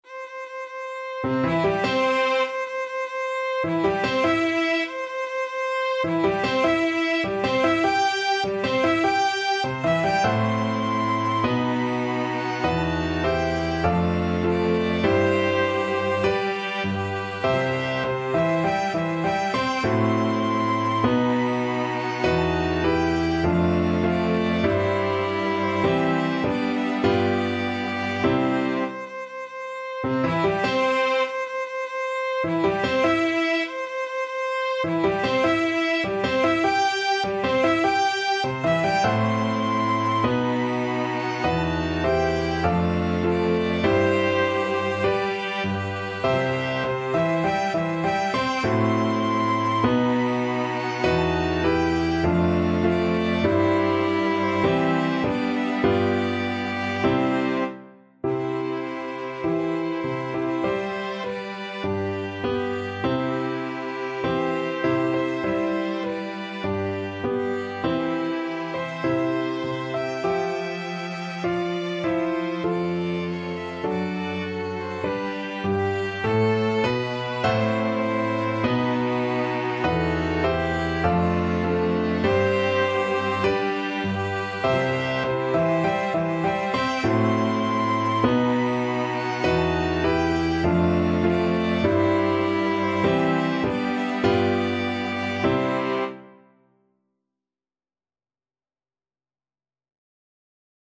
Free Sheet music for Piano Quintet
Violin 1Violin 2ViolaCelloPiano
C major (Sounding Pitch) (View more C major Music for Piano Quintet )
4/4 (View more 4/4 Music)
Andante maestoso =100
Classical (View more Classical Piano Quintet Music)
mendelssohn_wedding_march_PNQN.mp3